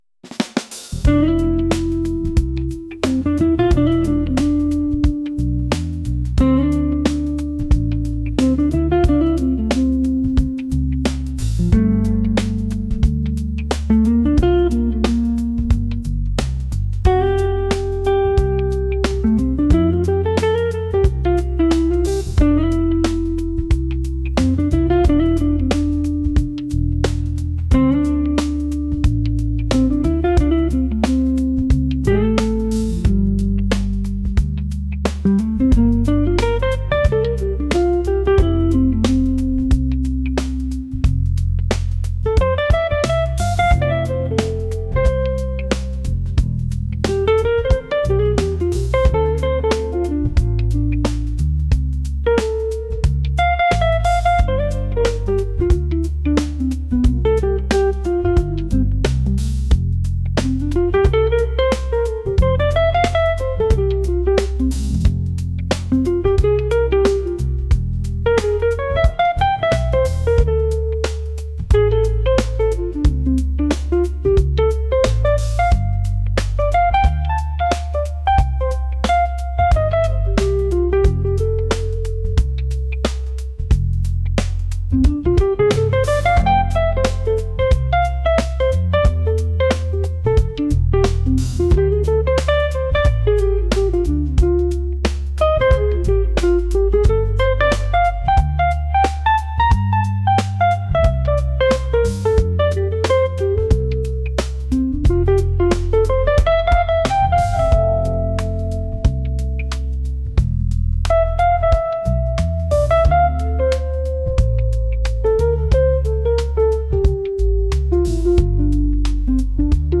smooth | jazz | soulful